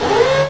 assemblerStart.ogg